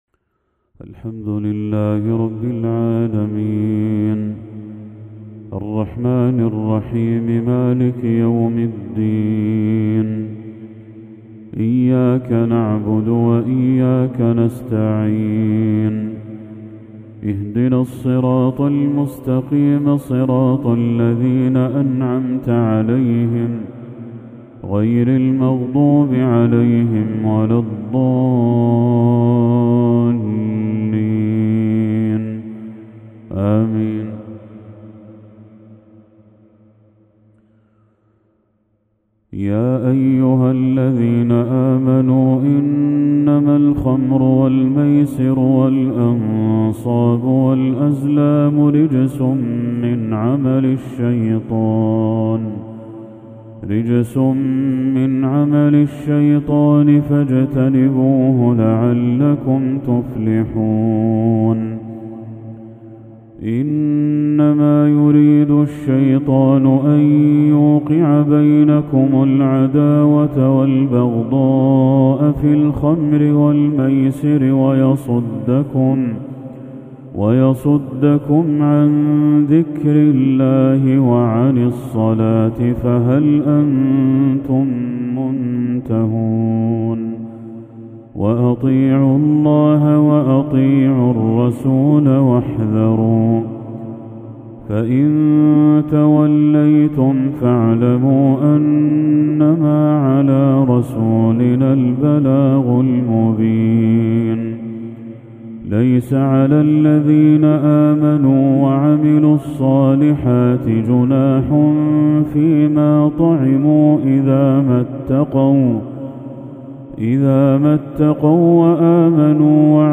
تلاوة عذبة من سورة المائدة للشيخ بدر التركي | عشاء 3 ذو الحجة 1445هـ > 1445هـ > تلاوات الشيخ بدر التركي > المزيد - تلاوات الحرمين